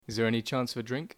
Examples of RP
//ɪz ðeə ˈeni tʃɑːns əv ə drɪŋk//
07_RP.mp3